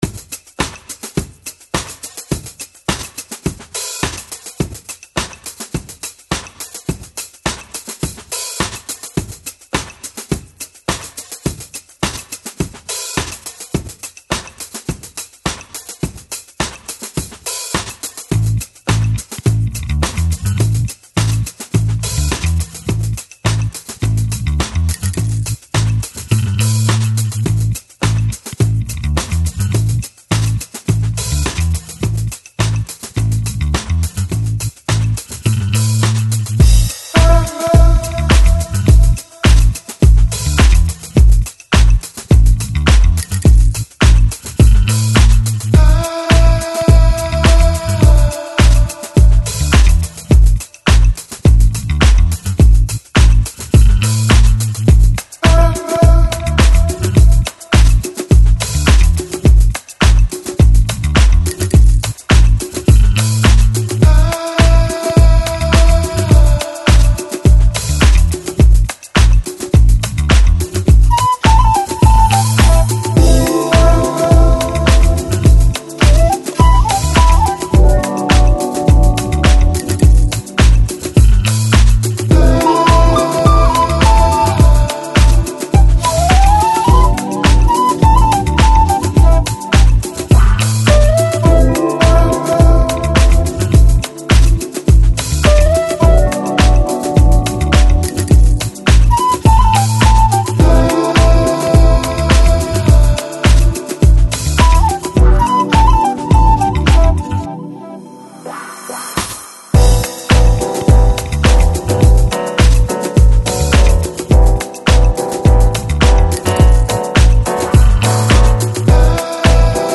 Жанр: Electronic, Lounge, Chill Out, Downtempo, Balearic